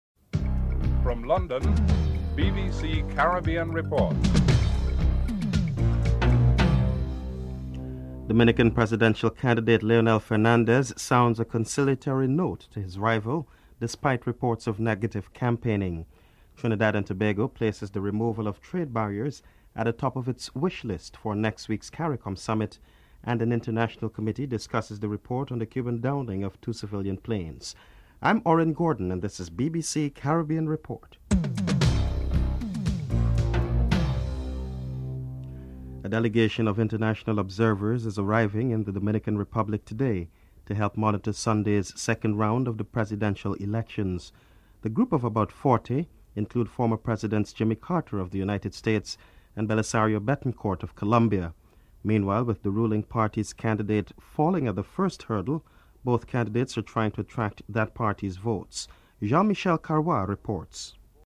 1. Headlines (00:00:34)
Prime Ministers Basdeo Panday and Keith Mitchell are interviewed.
4. US and Venezuelan officials have agreed that the UN Seurity Council should extend a period of deployment of its peace keeping forces in Haiti for another five months. Venezuela's Ambassador to the United Nations is interviewed (07:41-08:25)